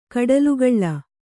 ♪ kaḍalugaḷḷa